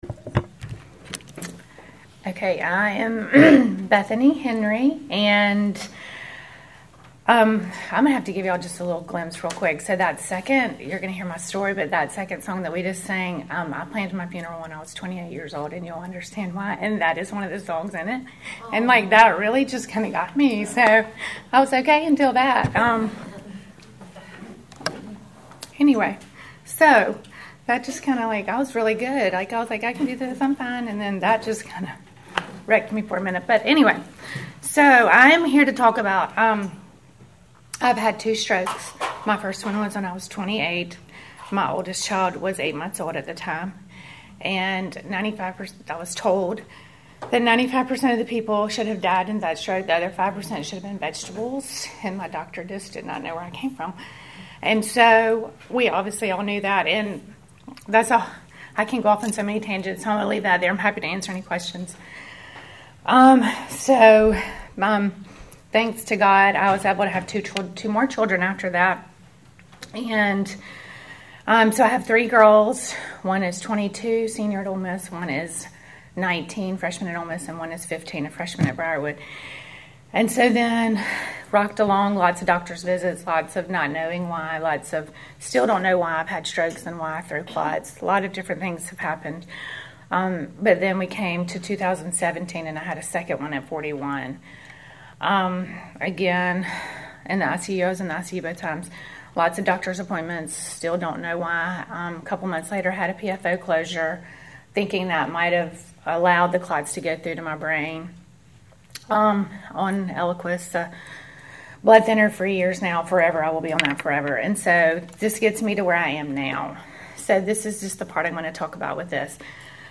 Welcome to the sixteenth lesson in our series WAITING ON GOD!
We will hear testimonies from 4 ladies in our study and what waiting on the Lord looked like for them this year.